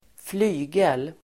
Uttal: [²fl'y:gel]